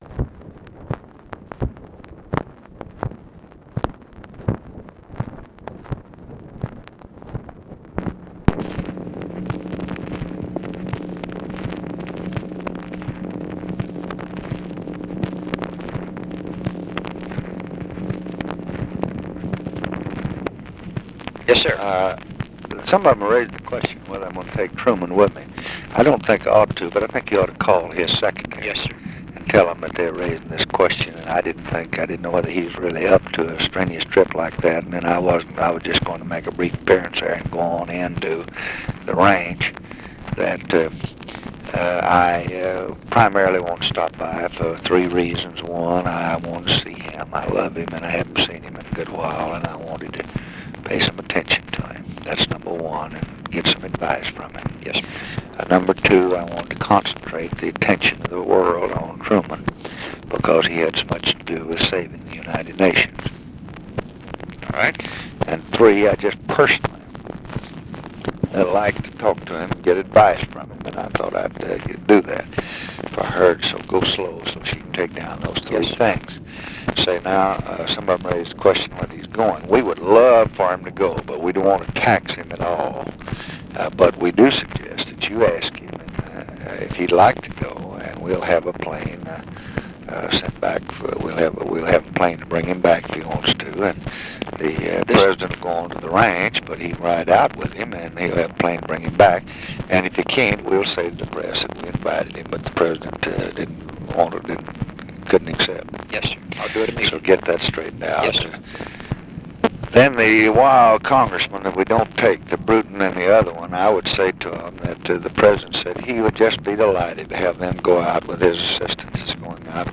Conversation with JACK VALENTI, June 24, 1965
Secret White House Tapes